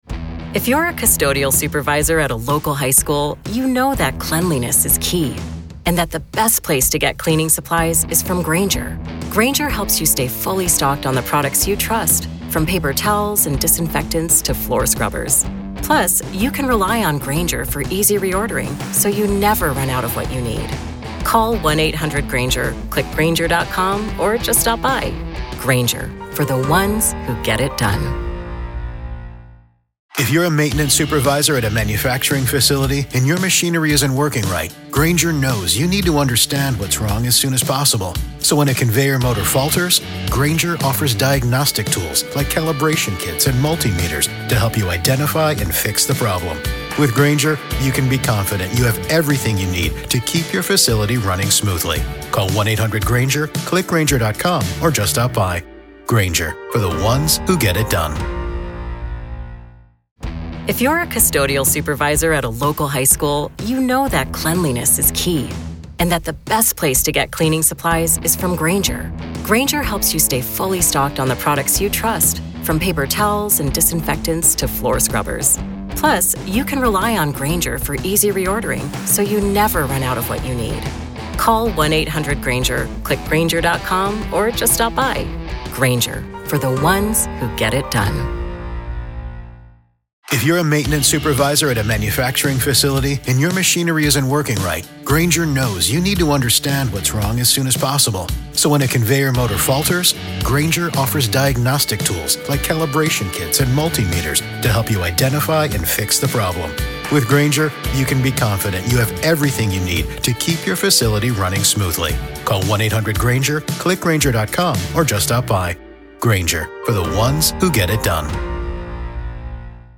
The team points out how her tone shifts depending on the question — at times defiant, at times almost childlike.
The hosts even share their own experiences being interrogated for unrelated reasons, showing just how intimidating the process can be.